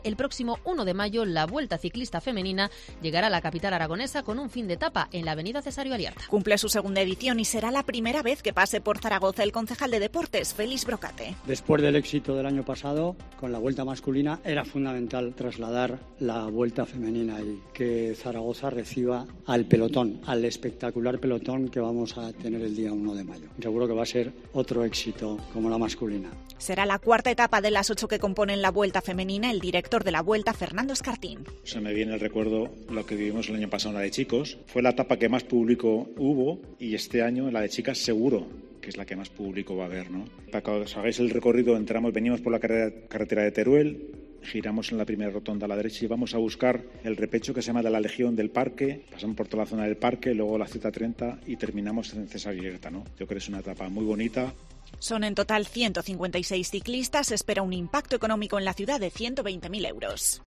en la presentación de la Vuelta.